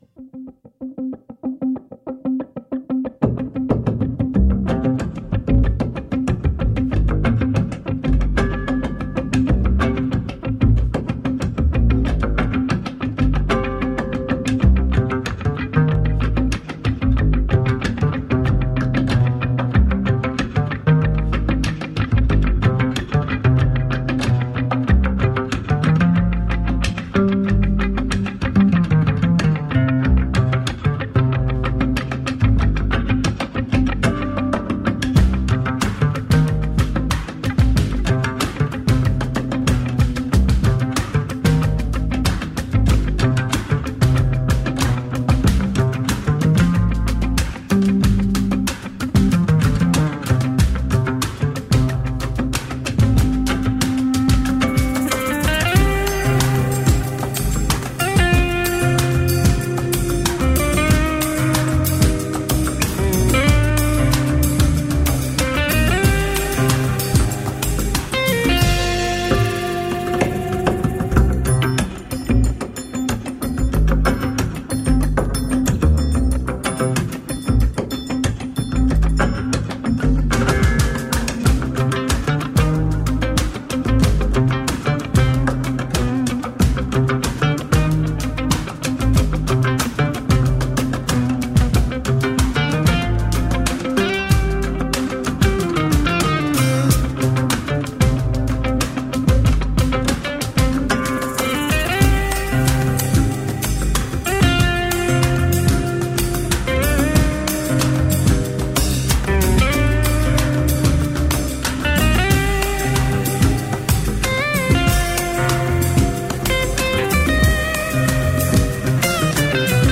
Interviews and Live Sessions